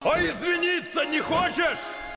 мемы , голосовые